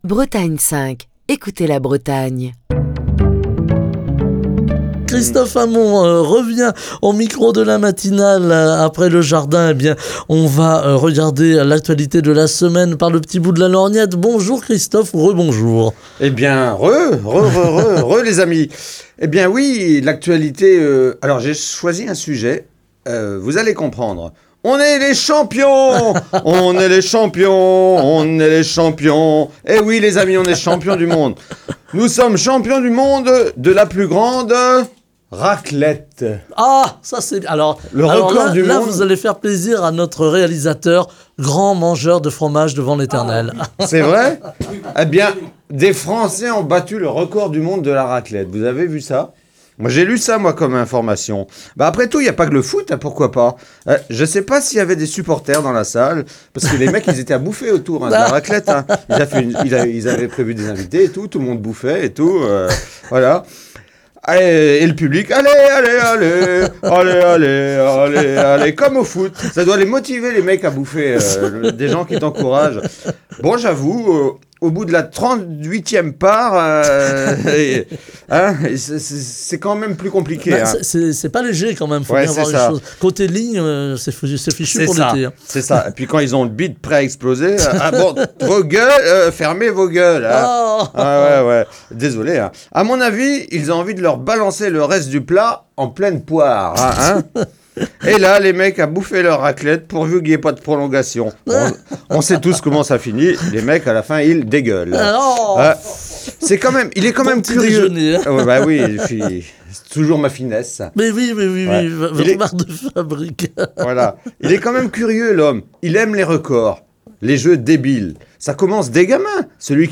Chronique du 22 mars 2024.